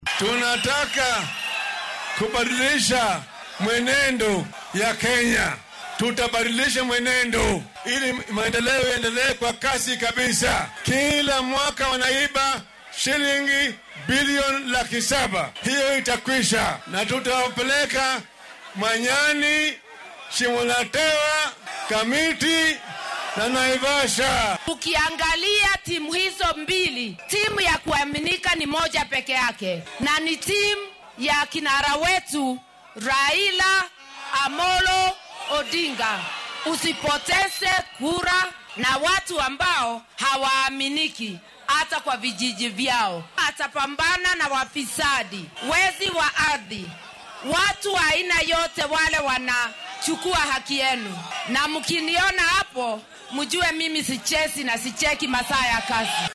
Musharraxa madaxweyne ee isbeheysiga Azimio Raila Odinga iyo ku xigeenkiisa Martha Karua oo shalay isku soo bax siyaasadeed ku qabtay ismaamulka Narok ayaa sheegay in haddii 9-ka bisha Siddeedaad ee sanadkan ay qabtaan hoggaanka dalka ay si wayn ula dagaallami doonaan musuqmaasuqa.